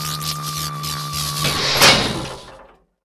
beampower.ogg